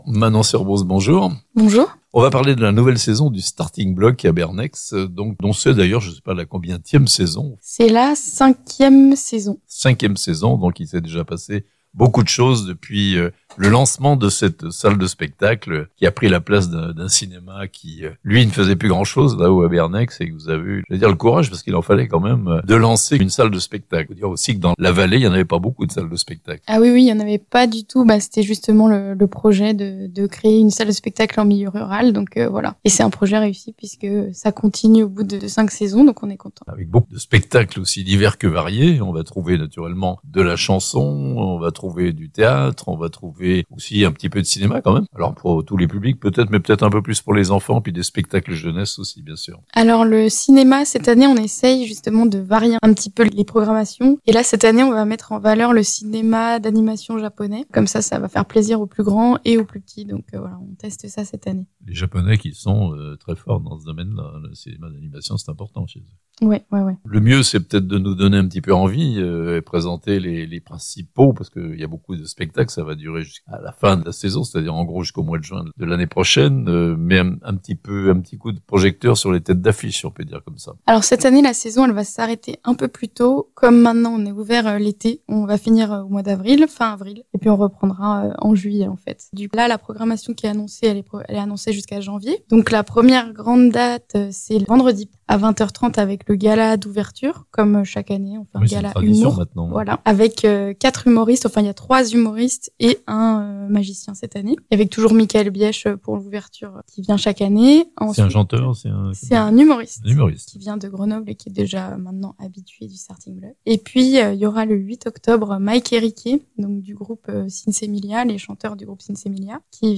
Une 6ème saison de spectacles pour le Starting-Block à Bernex (interview)